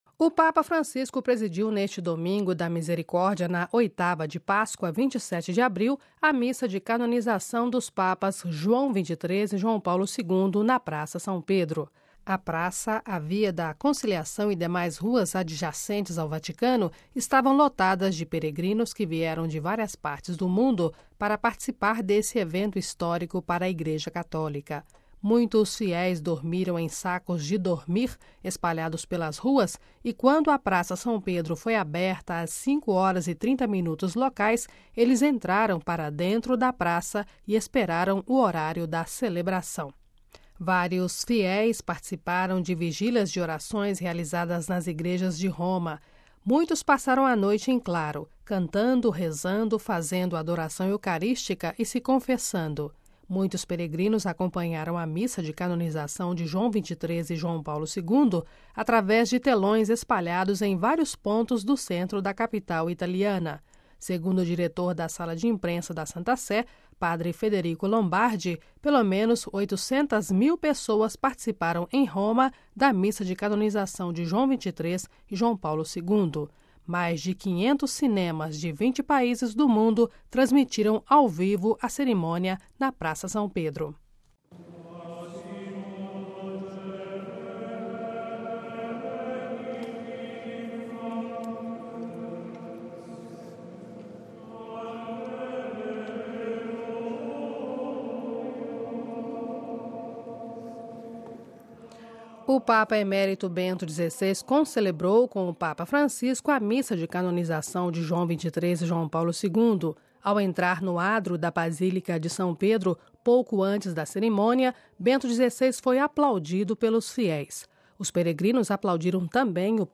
Cidade do Vaticano (RV) - Cidade do Vaticano (RV) - O Papa Francisco presidiu neste Domingo da Misericórdia na Oitava de Páscoa, 27 de abril, a missa de canonização dos Papas João XXIII e João Paulo II, na Praça São Pedro.
O Santo Padre logo depois proclamou oficialmente a santidade dos dois Papas sob os aplausos dos presentes, proferindo a seguinte fórmula de canonização: